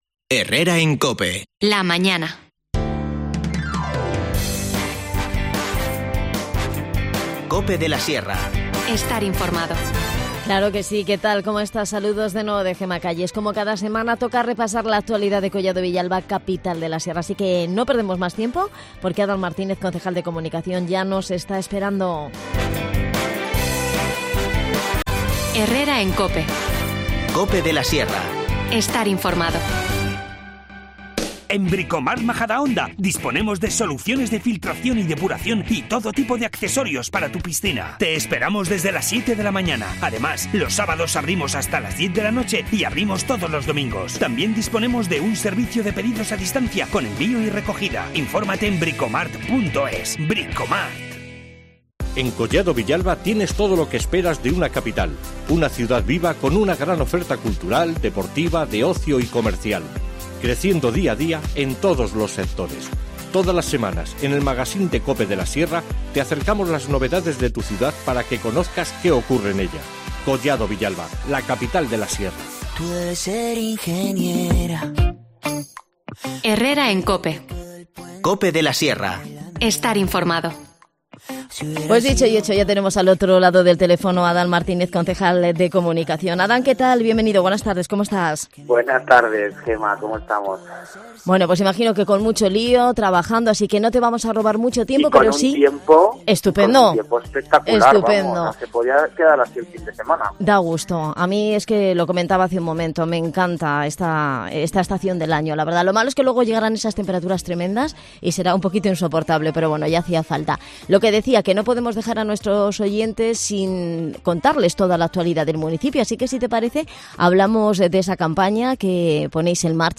AUDIO: Adan Martínez, concejal de Comunicación, aborda toda la actualidad de Collado Villalba,Capital de la Sierra, que pasa por la puesta en...